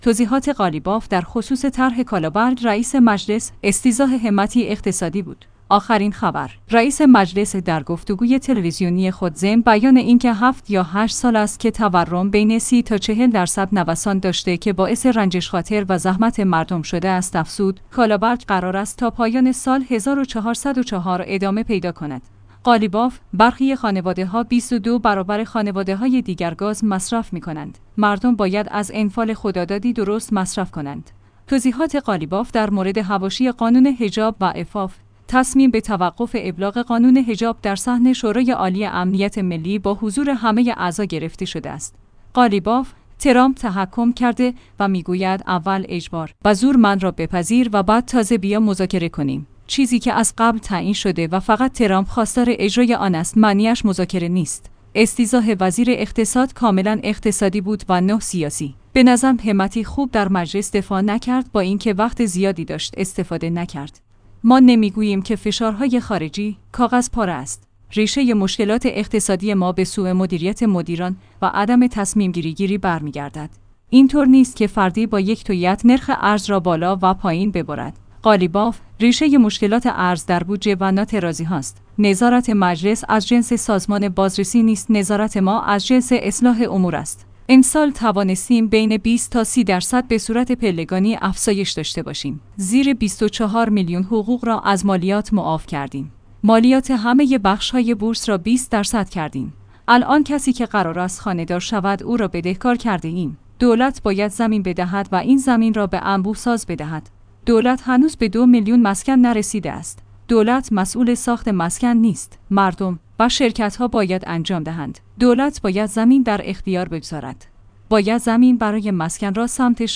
آخرین خبر/ رئیس مجلس در گفت‌وگوی تلویزیونی خود ضمن بیان اینکه ۷ یا ۸ سال است که تورم بین ۳۰ تا ۴۰ درصد نوسان داشته که باعث رنجش خاطر و زحمت مردم شده است افزود: کالابرگ قرار است تا پایان سال ۱۴۰۴ ادامه پیدا کند.